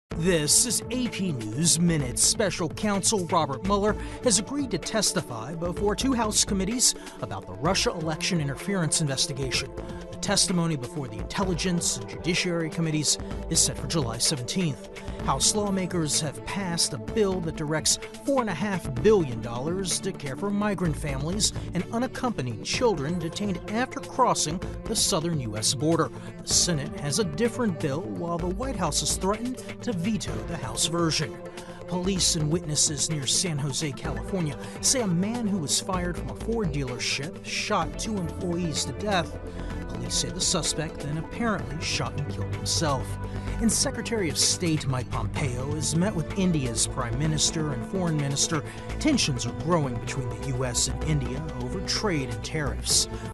美语听力练习素材:国务卿蓬佩奥会见印度总理和外长|美语听力练习素材
News